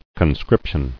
[con·scrip·tion]